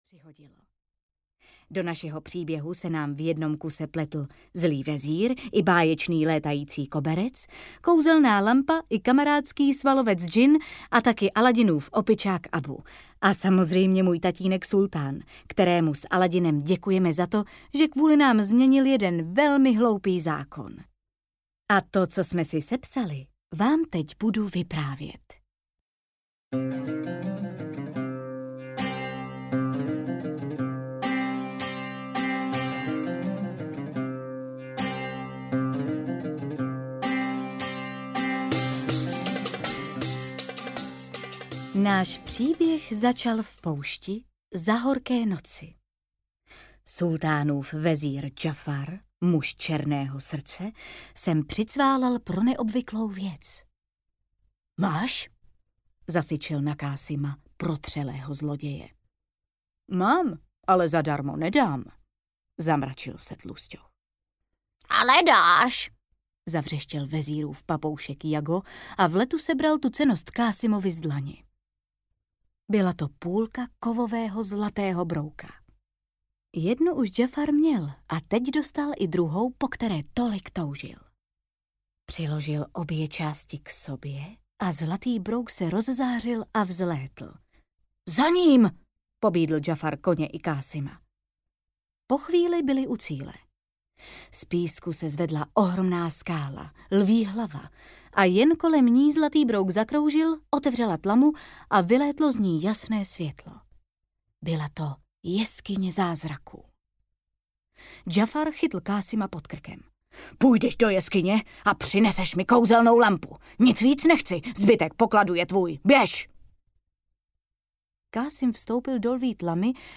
Disney - Aladin, Auta, Petr Pan audiokniha
Ukázka z knihy